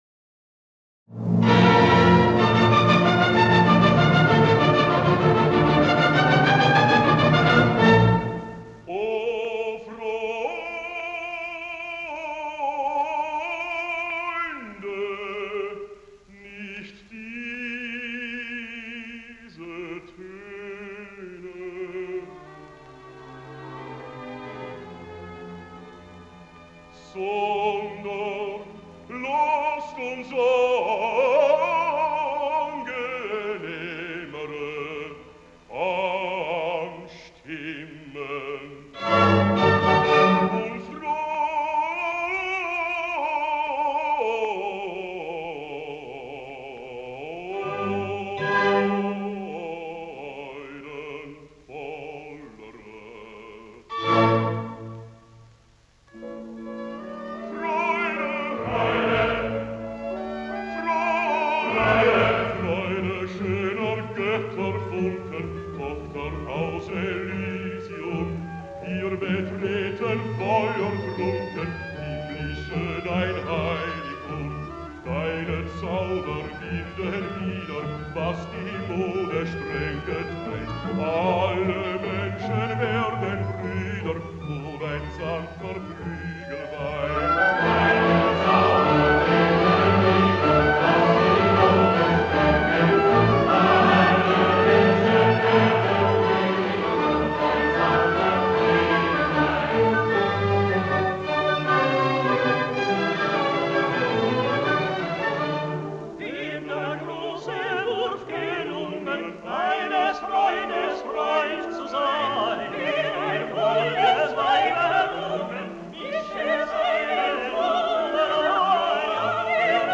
このCDは、過去半世紀以上に及ぶ全曲録音から16人のバリトン（またはバス）歌手によるレチタティーヴォを抜き出したものである。
1. RICHARD MAYR (Baritone)
Vienna Philharmonic Orchestra
cond. by Felix Weingartner